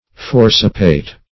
\For"ci*pate\